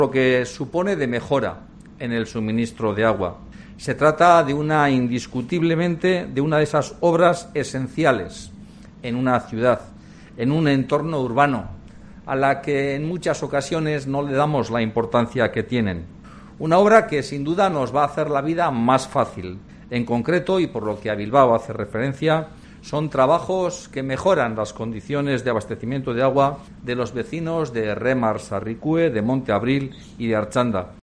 Juan Mari Aburto, alcalde de Bilbao